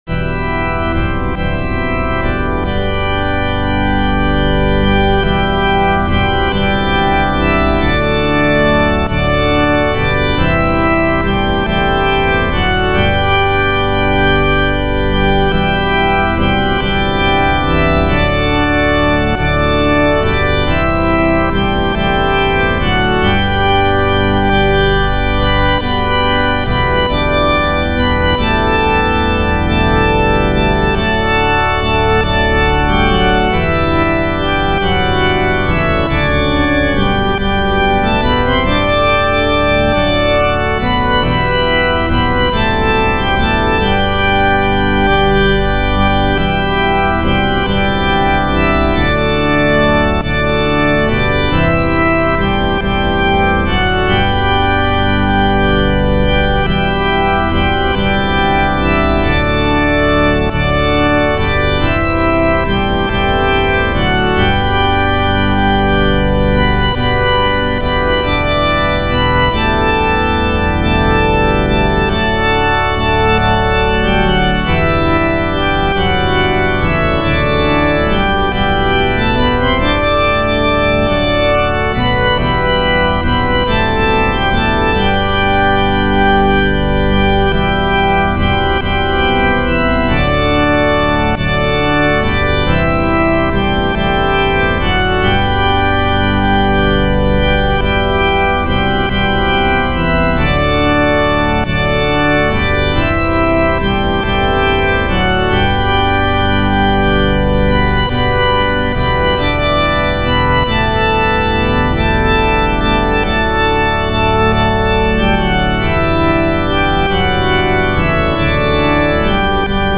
The tune in BEREDEN VAG FOR HERRAN, a seventeenth century Swedish tune, which I have also seen called MESSIAH.
I like this tune a lot for its invention and uplifting nature.
That didn’t stop me from cribbing the chords from the MIDI file on Hymnary and attacking it with fake BIAB organ.
(NB the first line of the melody is repeated – does that it make it the blues?)